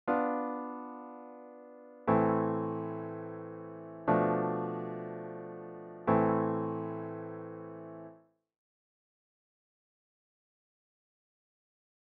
Here's these versions of Two / Five / One in A minor, our relative minor key to C major.
Kind of 'dirgey' huh? Although there is a bit of a gospel amen quality to it all thanks to the two pitch tritone within.